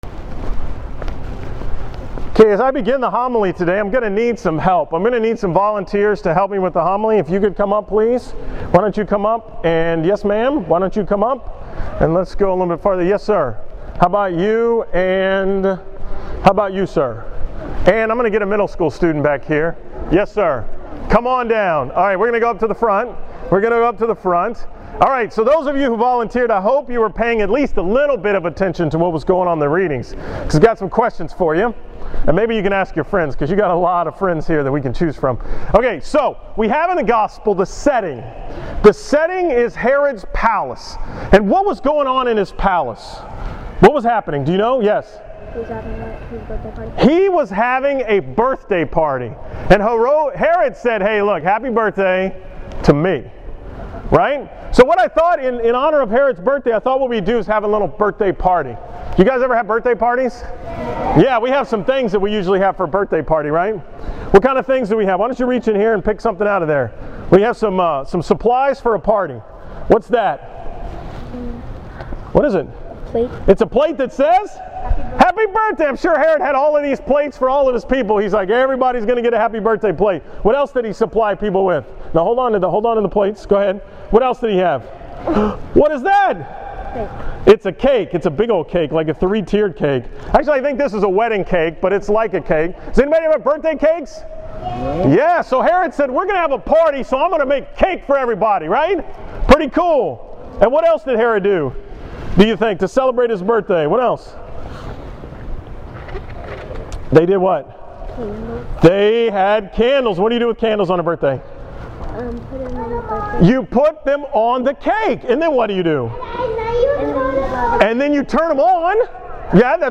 From John Paul II school on Friday, August 29, 2014
Category: 2014 Homilies, School Mass homilies